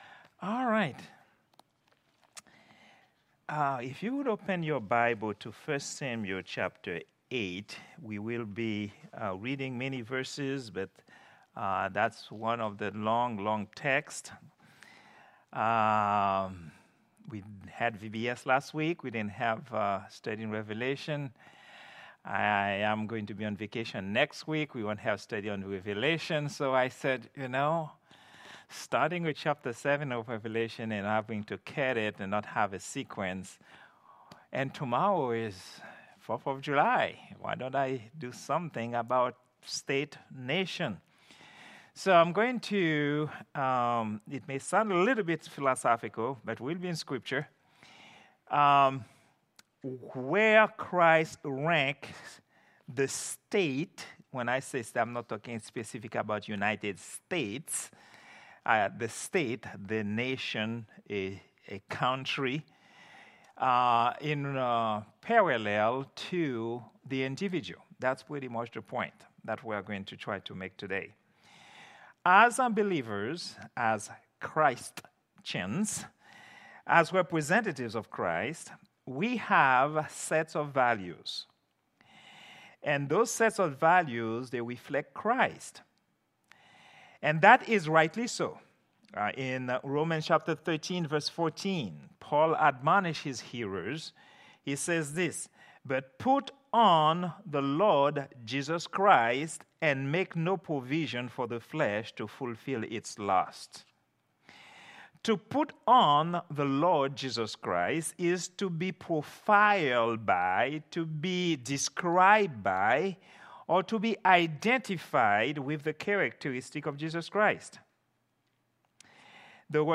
Prayer_Meeting_07_03_2024.mp3